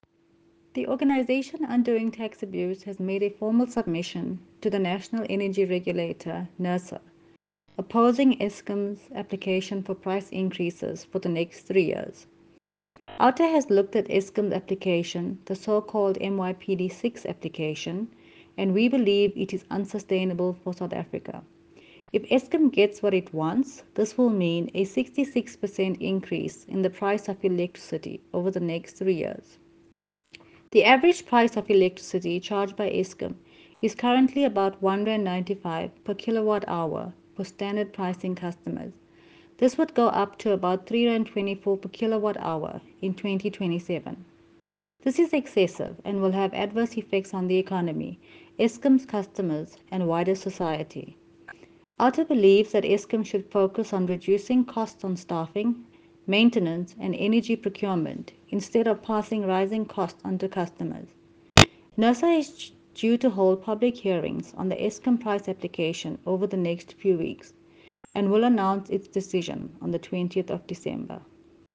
A soundclip with comment